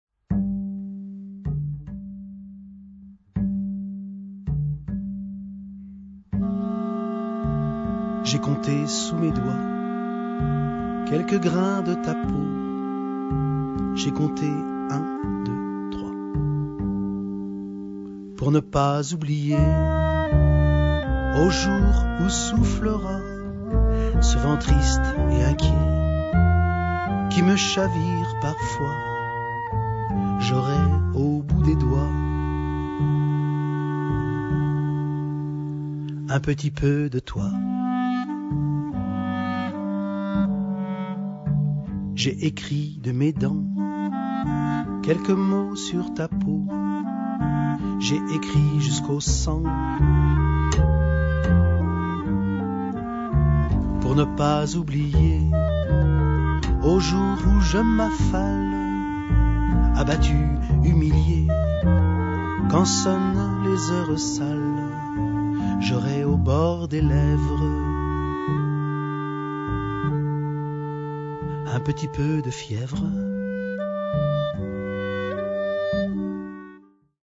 clarinettes, guitare, synthétiseur, percussions, voix